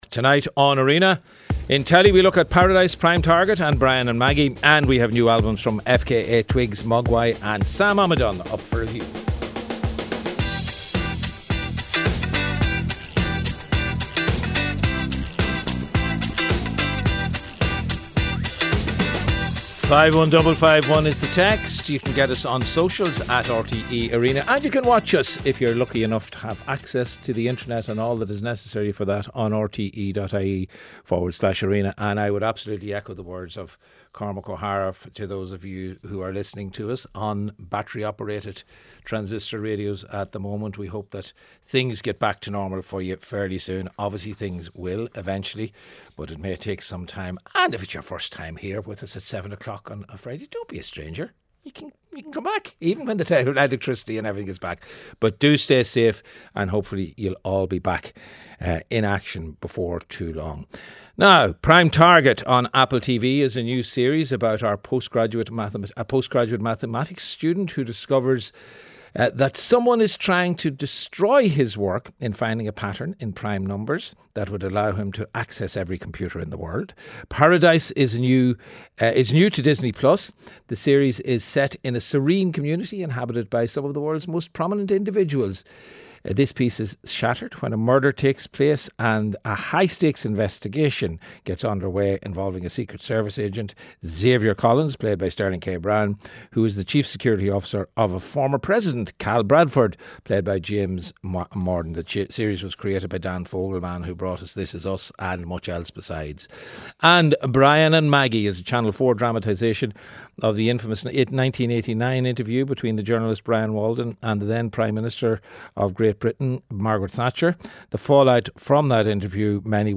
Irish podcast exploring what's happening in the world of arts, culture and entertainment. Listen live every weeknight on RTÉ Radio 1 from 7-8pm.